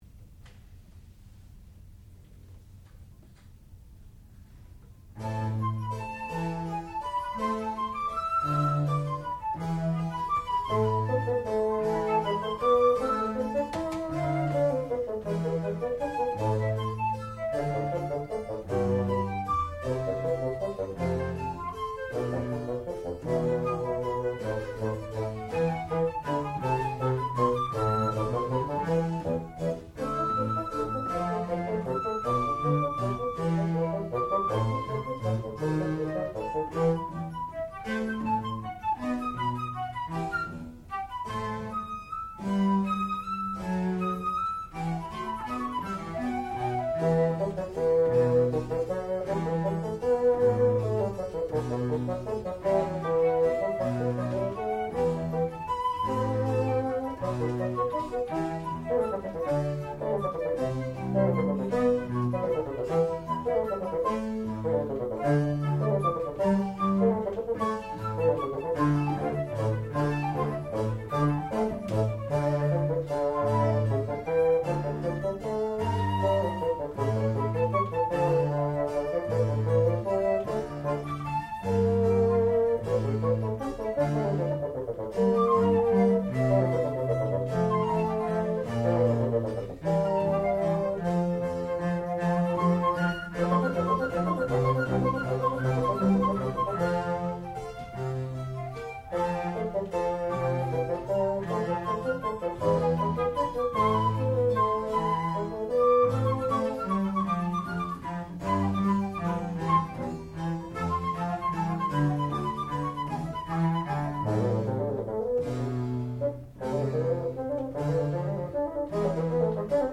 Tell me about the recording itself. Junior Recital